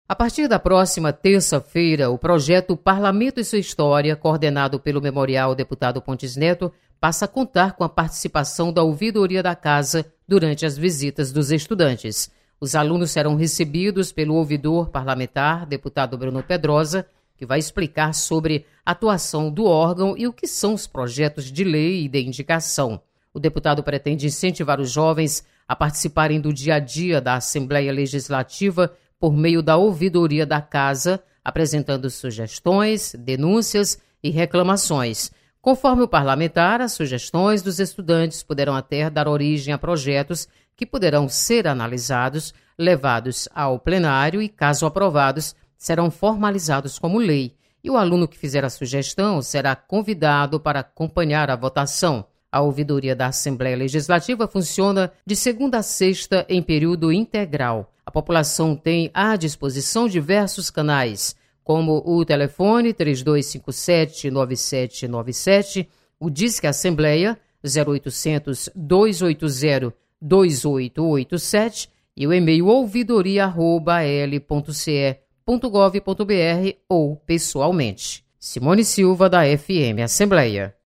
Ouvidoria da Assembleia quer se aproximar da juventude cearense. Repórter